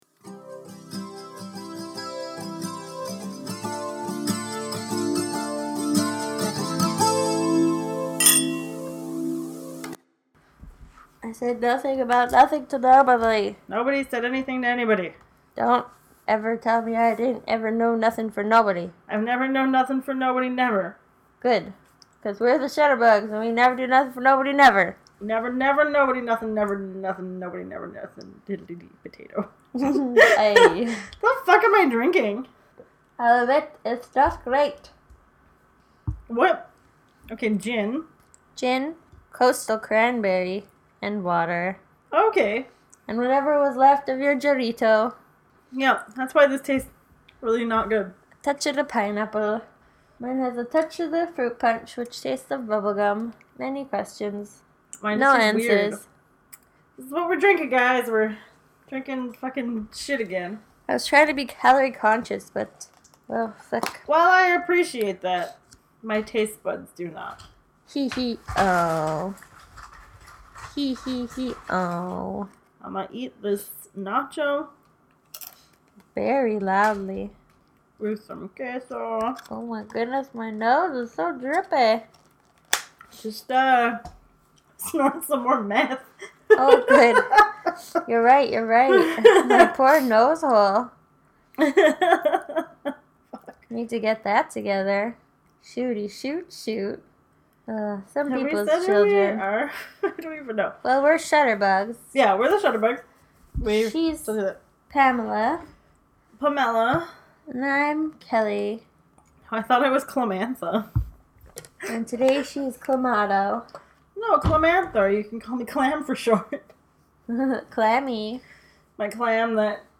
Every once in a while we record two movies in a row (sometimes three), and this is the beautiful drunken mess that results.